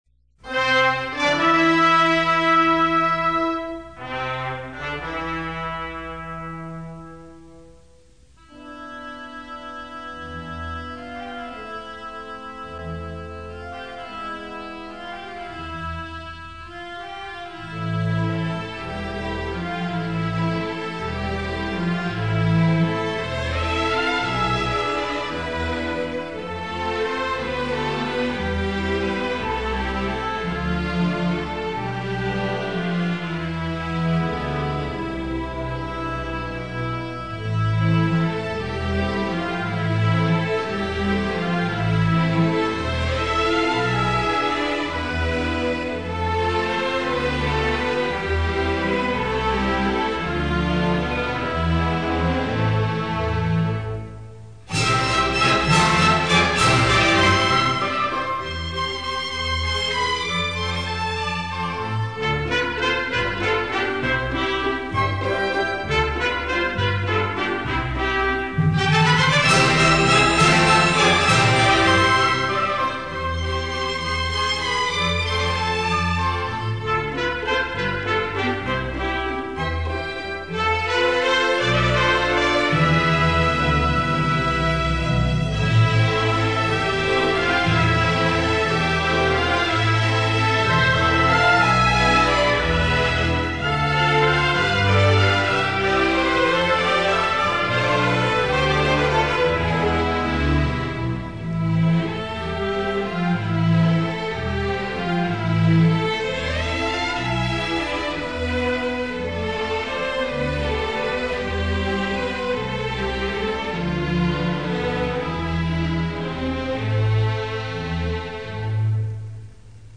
Coro y Orquesta de Cámara de Madrid
• El vals interludi del cuadre 2 – La musa francesa